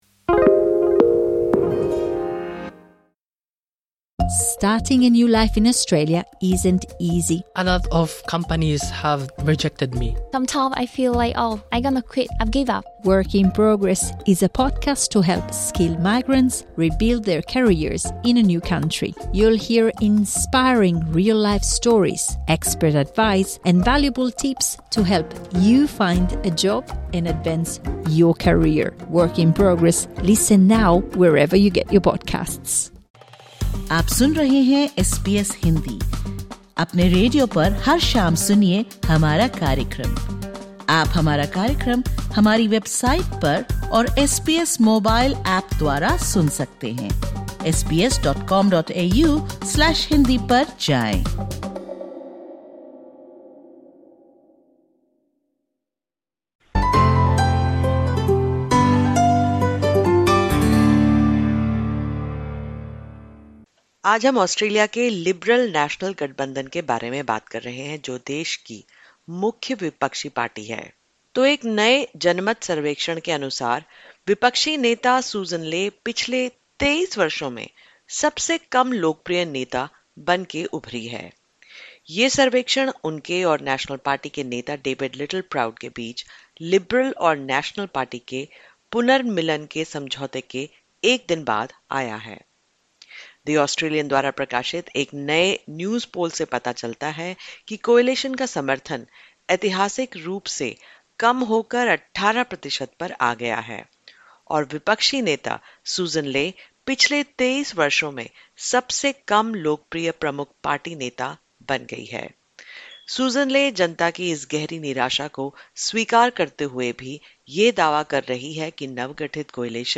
SBS Hindi spoke to some voters to find out what they want from the major political parties.